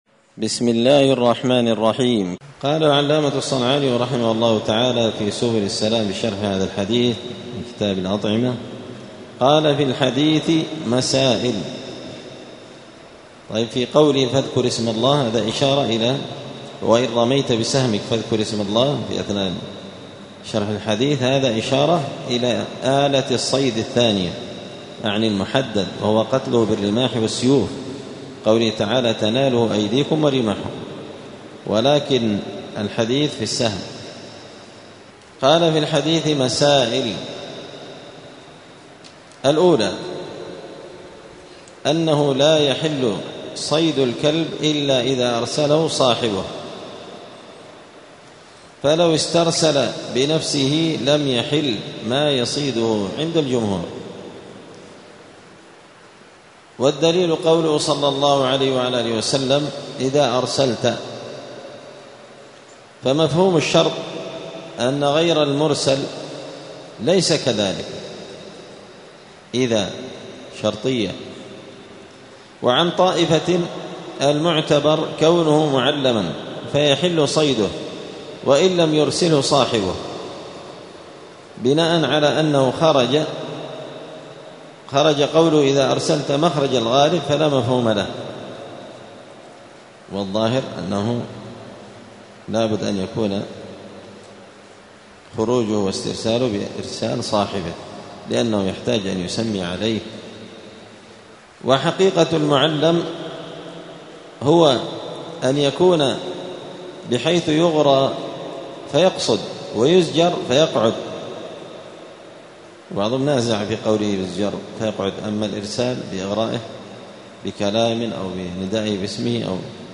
*الدرس العاشر (10) {باب الصيد والذبائح اﻟﺼﻴﺪ ﺑﻐﻴﺮ اﻟﻜﻼﺏ}*
دار الحديث السلفية بمسجد الفرقان قشن المهرة اليمن